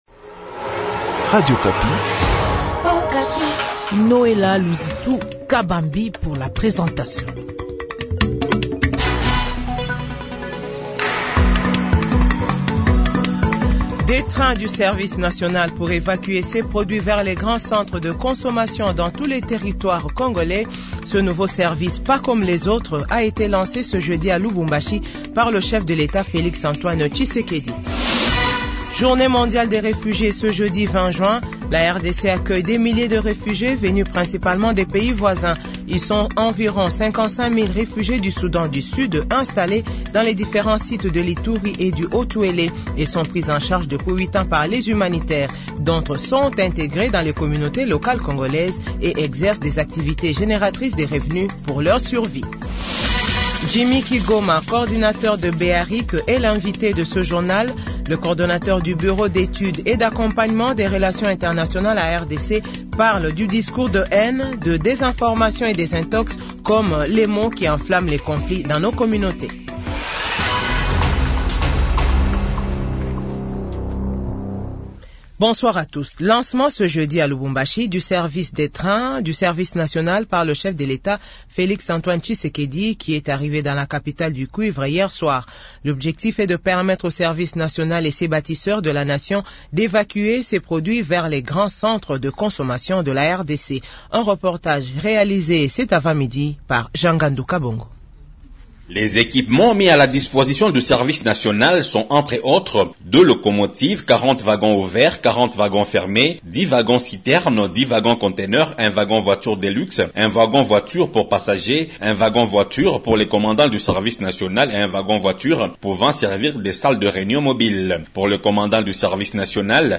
JOURNAL FRANCAIS 18H00